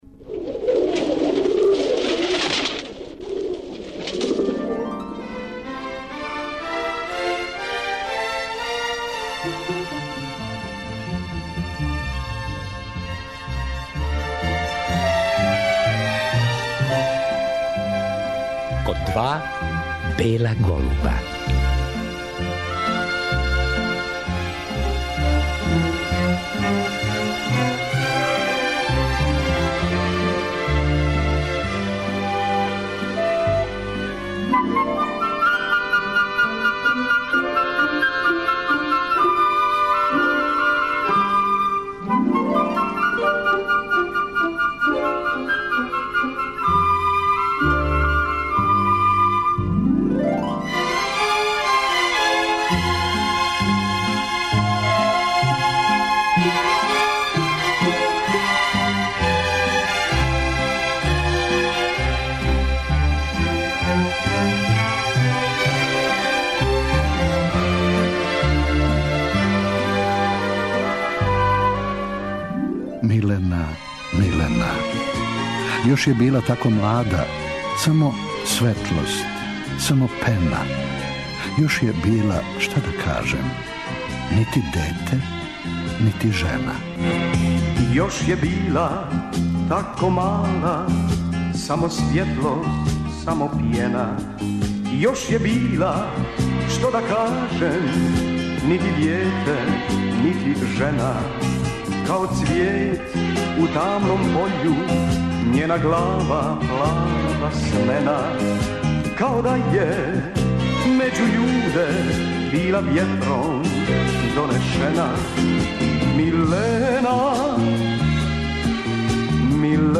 Том приликом снимили смо разговор са Миленом Дравић, који вечерас поново емитујемо.